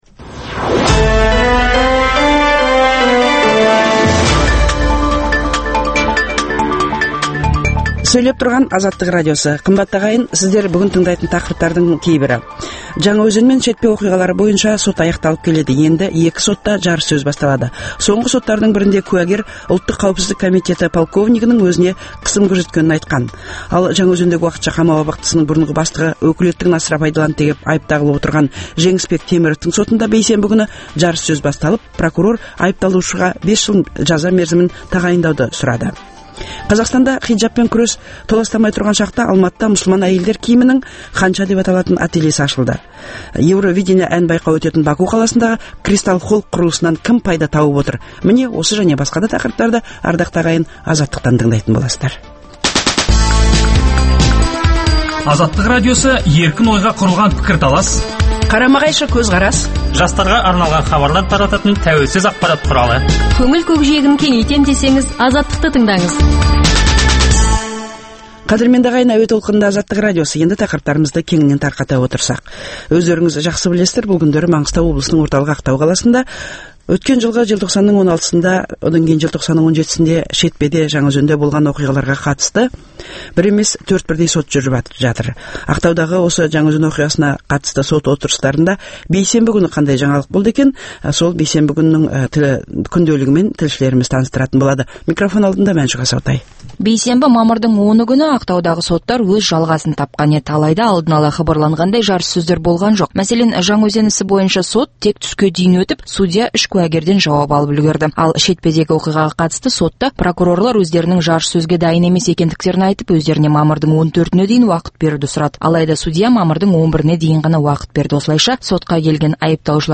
Ақтаудағы Жаңаөзен оқиғасына қатысты сот отырыстарында бейсенбі күні қандай жаңалық болды екен, тілшілеріміз әңгімелеп беретін болады. Жаңаөзен мен Шетпе оқиғалары бойынша сот аяқталып келеді, енді екі сотта жарыссөз басталады.